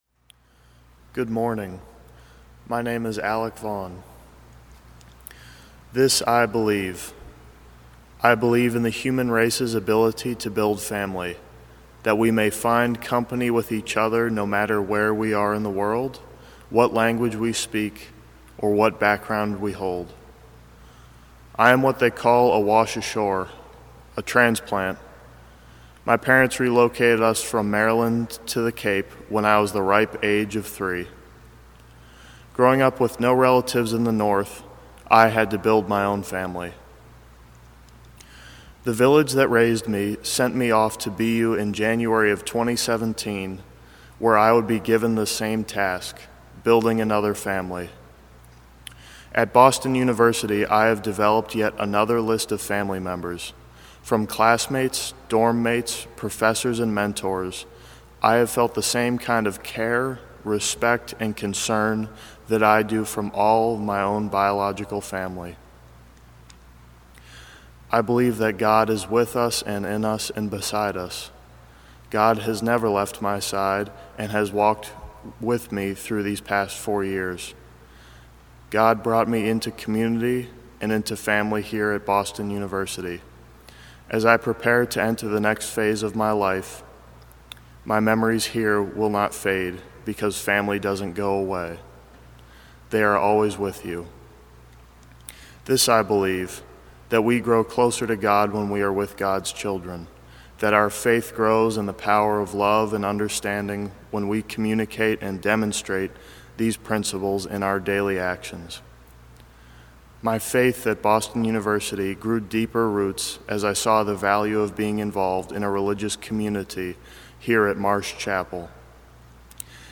Recording his reflection to an empty congregation, he says, proved to be a milestone.